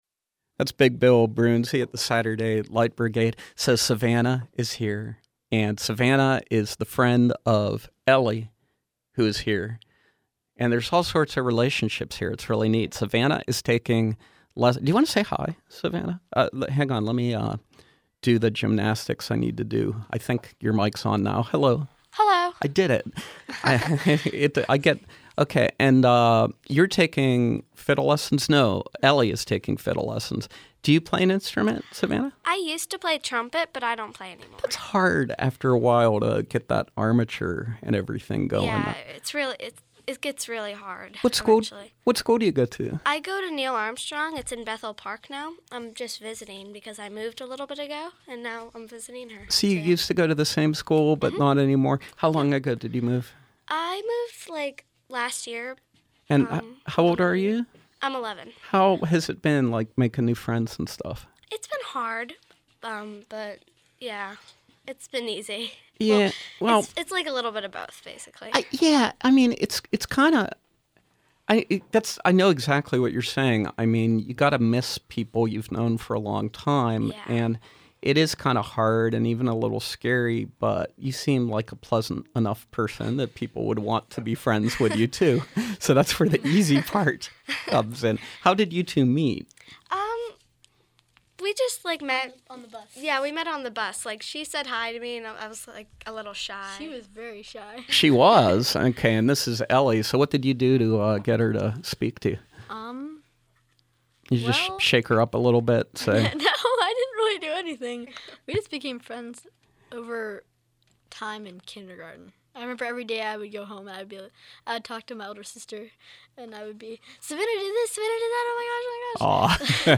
multi-instrumentalist